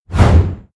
CHQ_VP_swipe.ogg